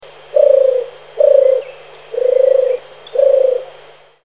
Tourterelle des bois
Streptopelia turtur